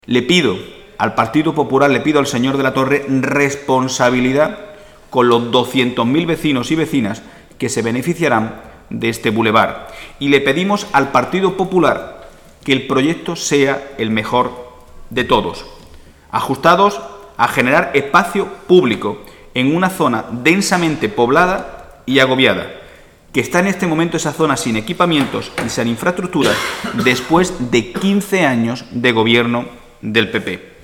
El secretario general del PSOE malagueño, Miguel Ángel Heredia, se ha referido hoy en rueda de prensa a la reunión sobre el soterramiento entre el Gobierno de España, en concreto Adif, y el Ayuntamiento de Málaga.